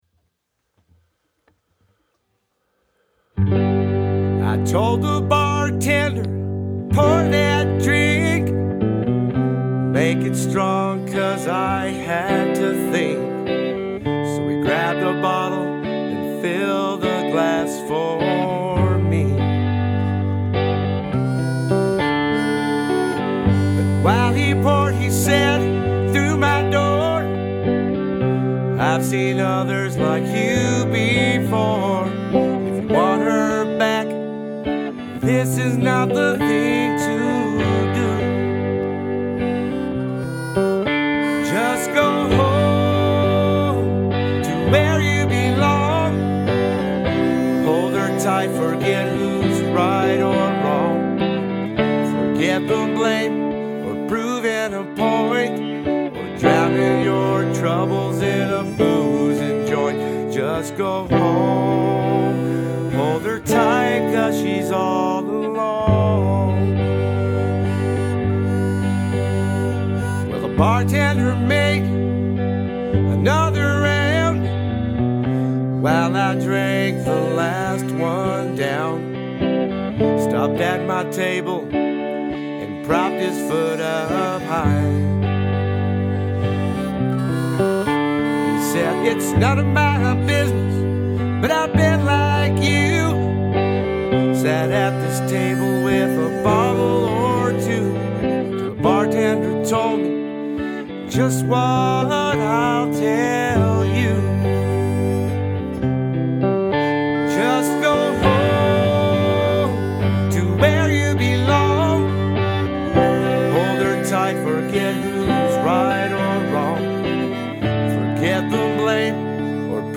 The Bartender’s Plea (Male Vocals